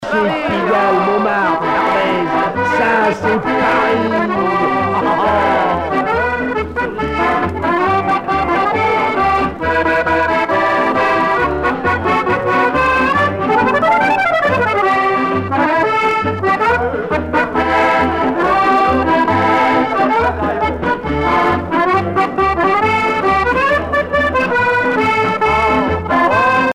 danse : one-step
Pièce musicale éditée